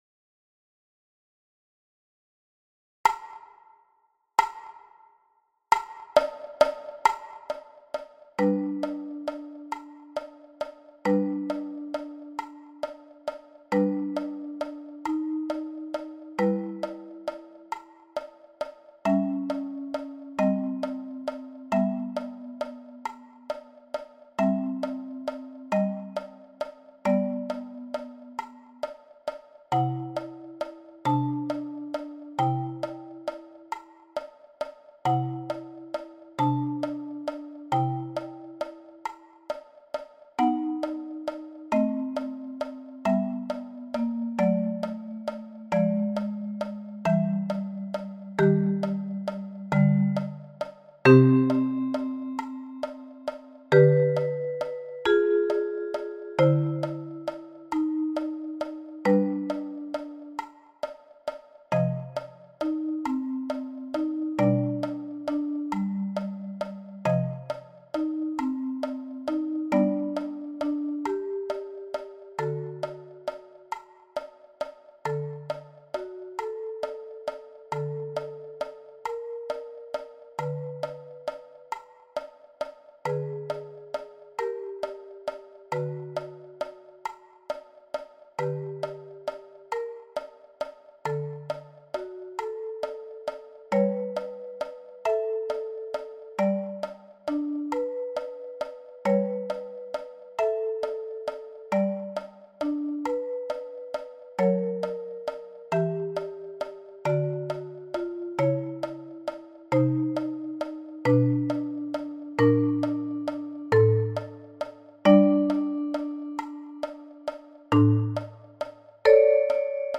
Sheherazade-prince-et-princesse-accompagnement-45-bpm.mp3